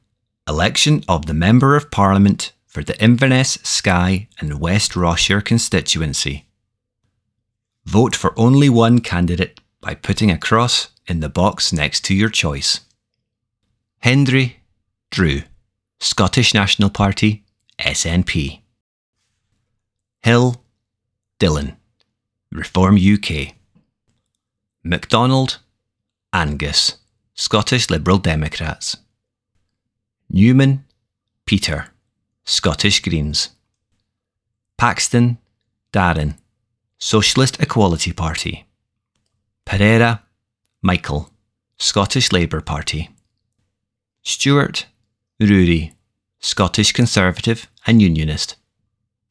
UK Parliamentary General Election 2024 - Spoken Ballot Papers | Inverness, Skye and West Ross-shire Ballot Paper